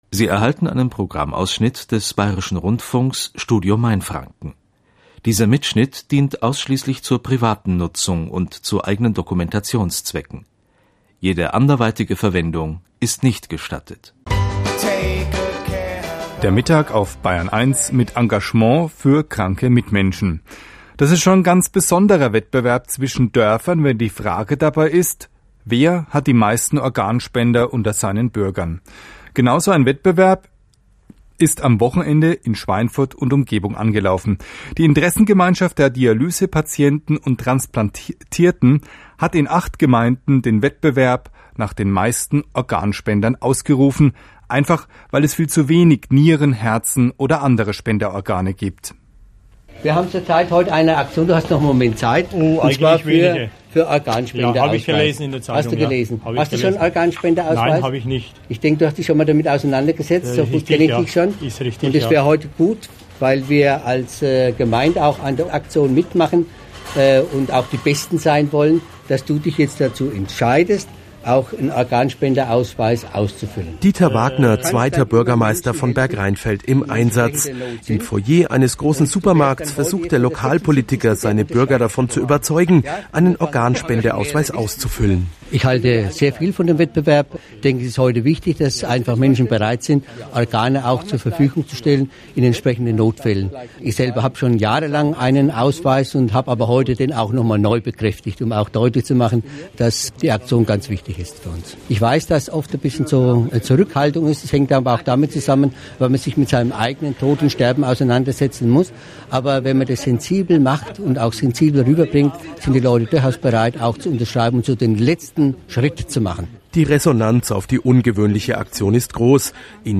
Radio-Beitrag zum Organspende-Wettbewerb - Bayern 1